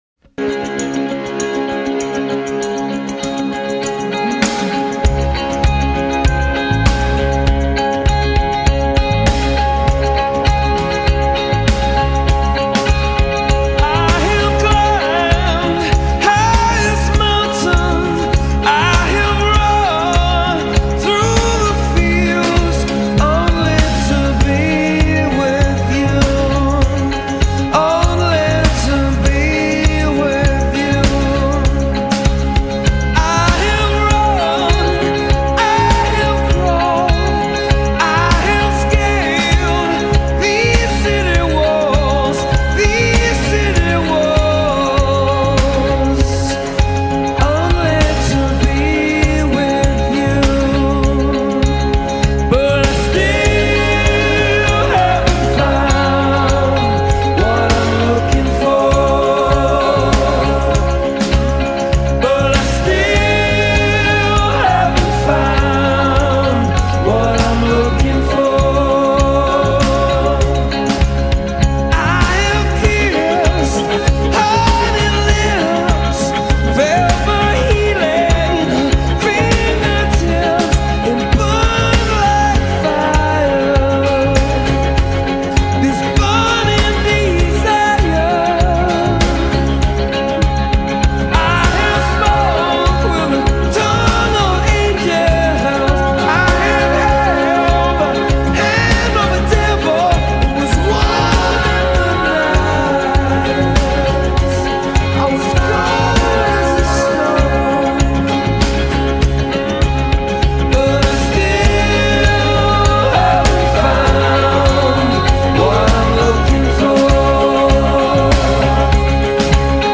Rock Para Ouvir: Clik na Musica.